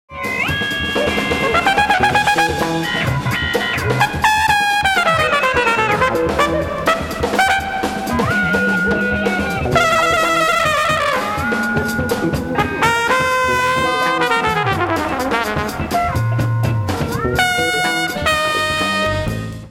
LIVE AT FILLMORE EAST, NEW YORK 06/17/1970
SOUNDBAORD RECORDING